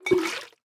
sounds / item / bottle / fill4.ogg